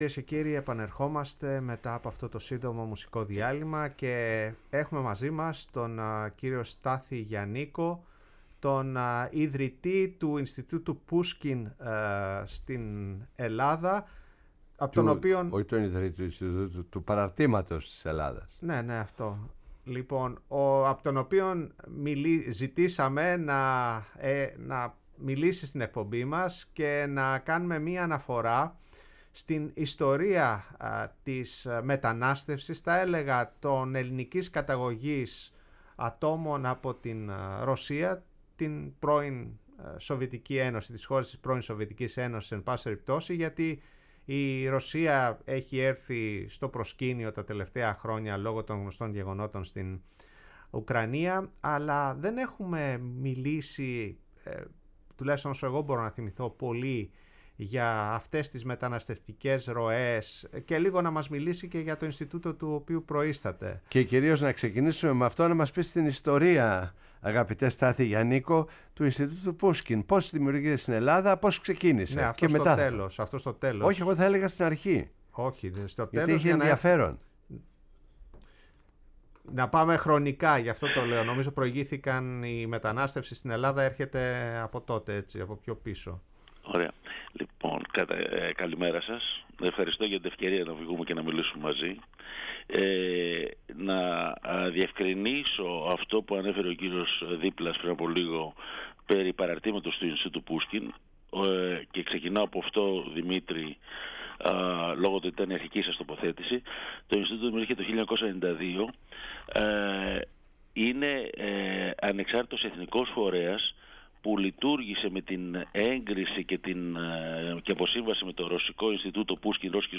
στο ραδιόφωνο της Φωνής της Ελλάδας